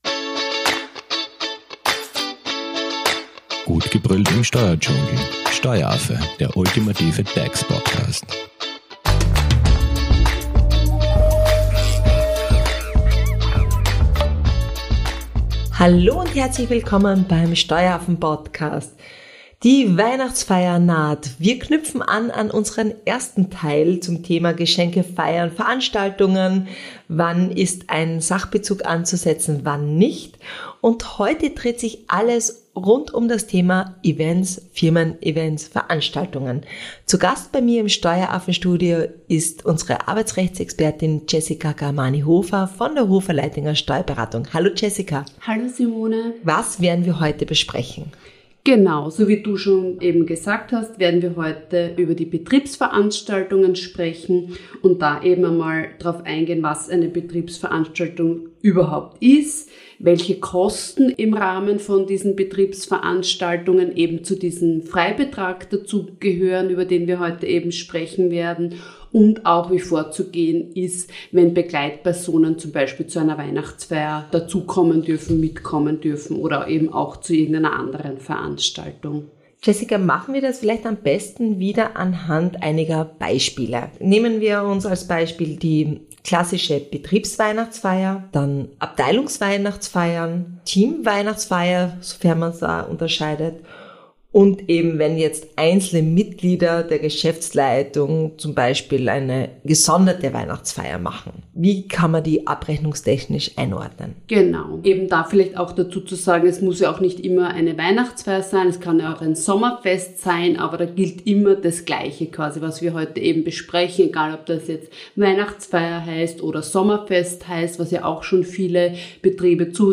ist zu Gast im Steueraffen-Studio und informiert ausführlich über die steuer- und abgabenrechtliche Behandlung von Firmen(-Weihnachts-)Feiern.